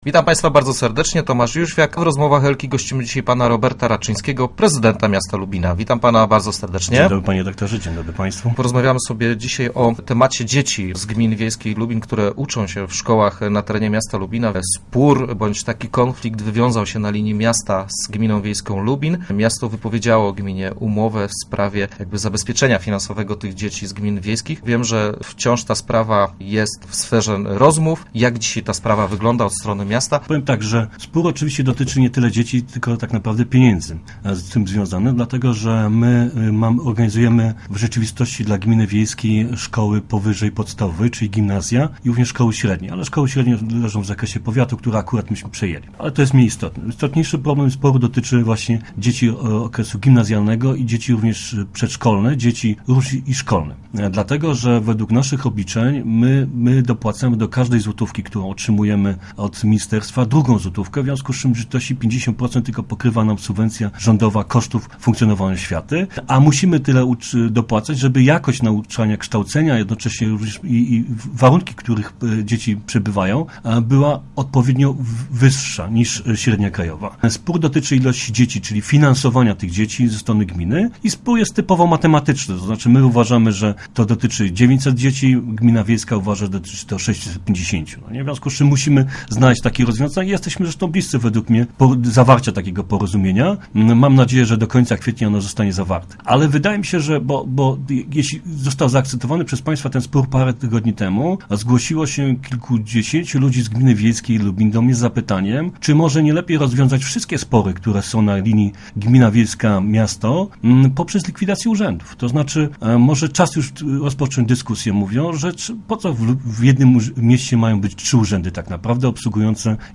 Start arrow Rozmowy Elki arrow Raczyński: Po co w jednym mieście trzy urzędy?
prezydentlubin.jpgJest szansa na porozumienie między miastem a gminą wiejską w sprawie dofinansowania kosztów nauki dzieci wiejskich kształcących się w lubińskich szkołach. Zadeklarował to prezydent Lubina, Robert Raczyński, który był naszym gościem.